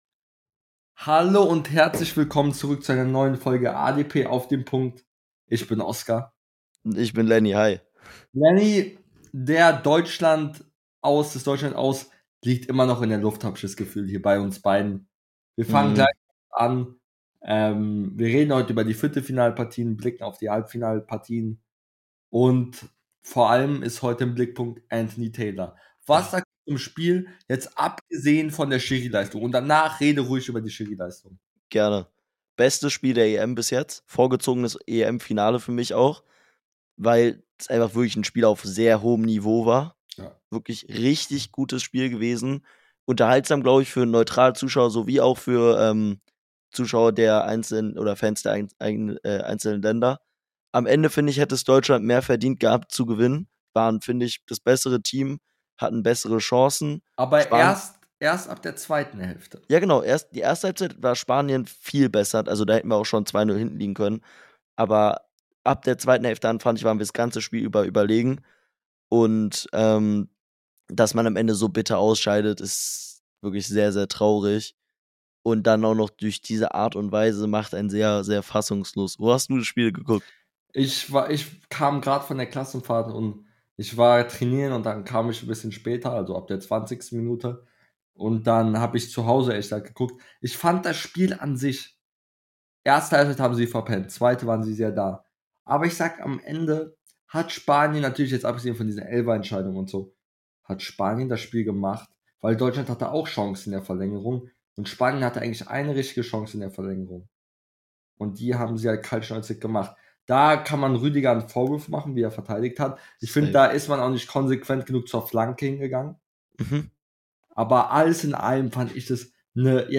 In der heutigen Folge reden die beiden Hosts über das Ausscheiden Deutschlands,Englands und Frankreichs Weg ins Halbfinale , Türkeis aus und vieles mehr